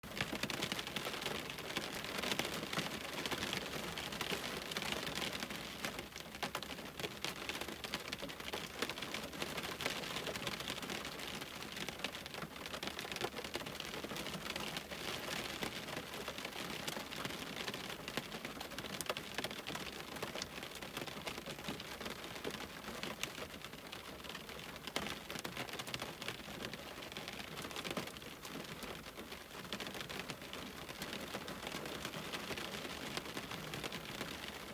rain-hitting-window.mp3